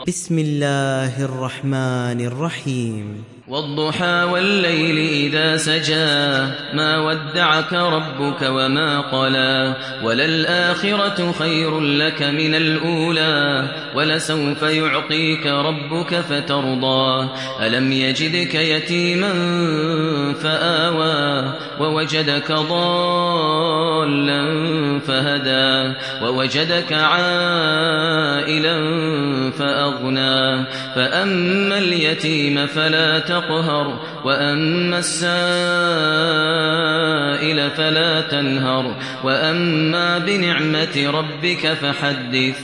Surat Ad Duhaa mp3 Download Maher Al Muaiqly (Riwayat Hafs)